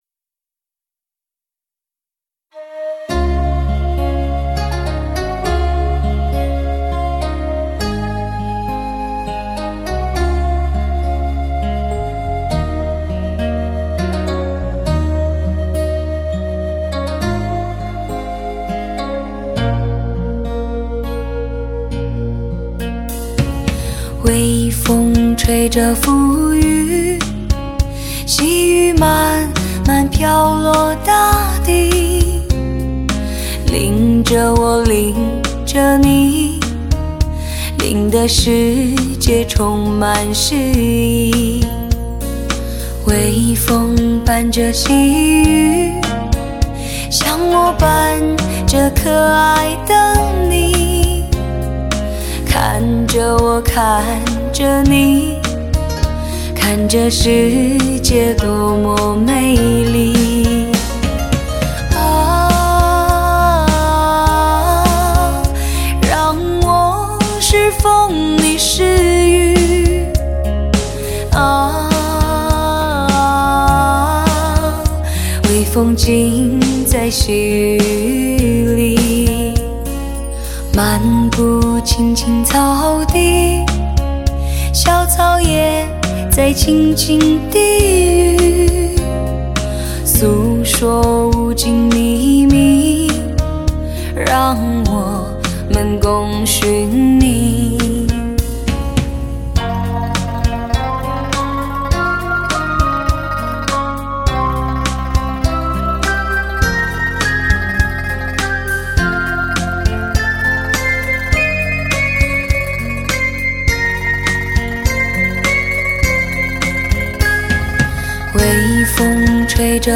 全方位多位环绕
发烧老情歌 纯音乐
极致发烧HI-FI人声测试碟